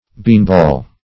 \bean"ball`\